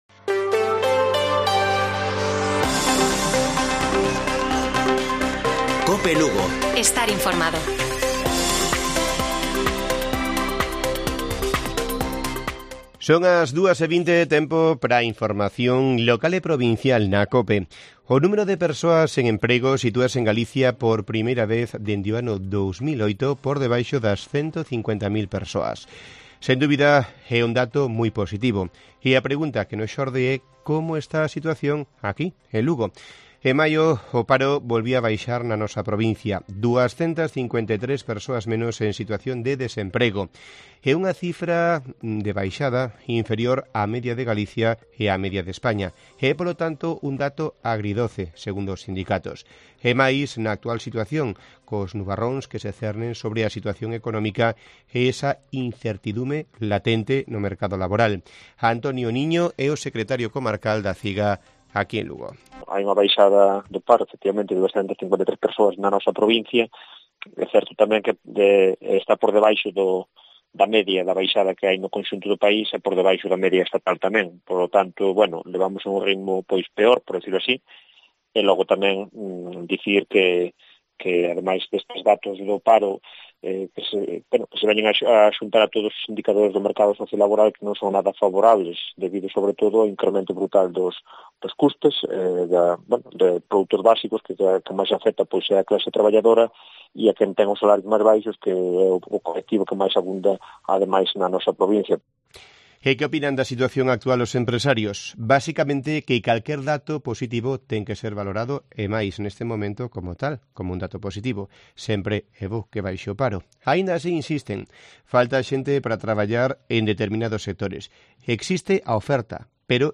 Informativo Mediodía de Cope Lugo. 02 DE JUNIO. 14:20 horas